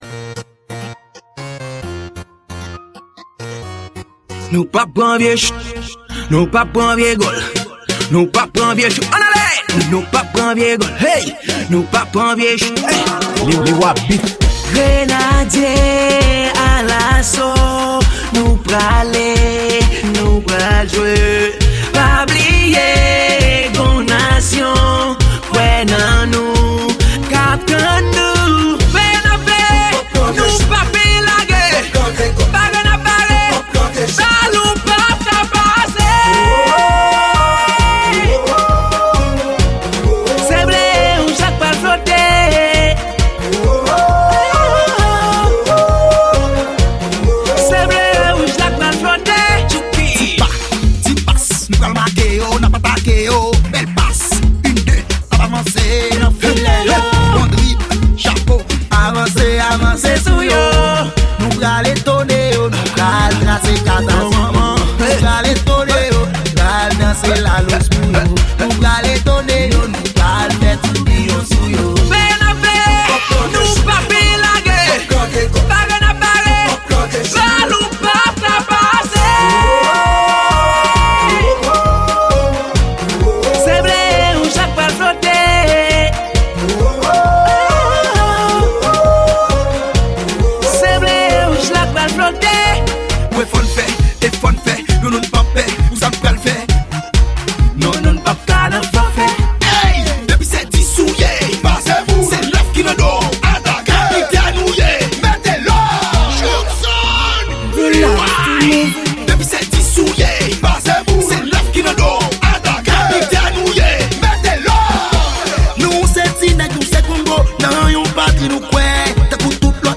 Genre: Varietes .